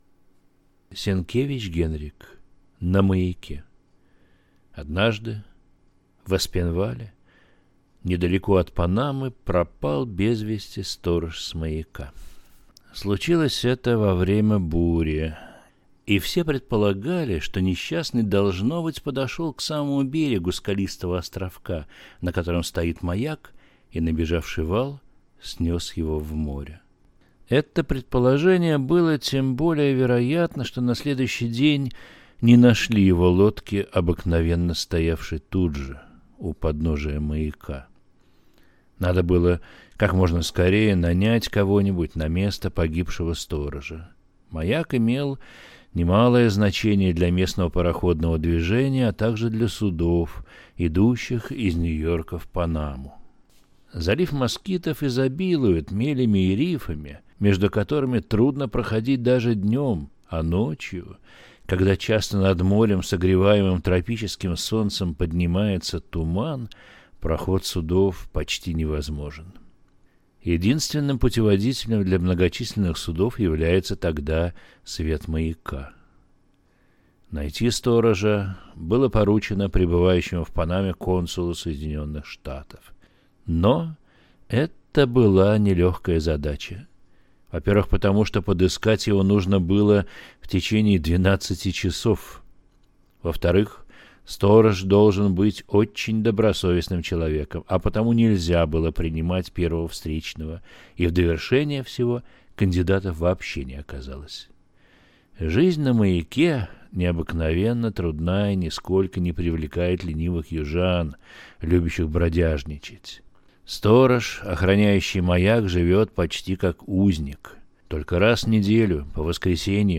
Aудиокнига На маяке